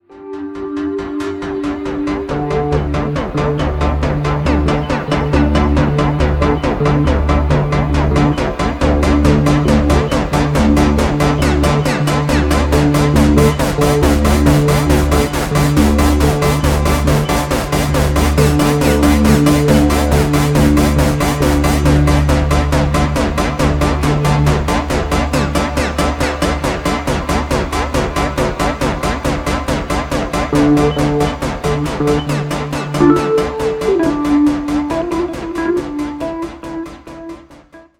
Electro jazzy pulsating.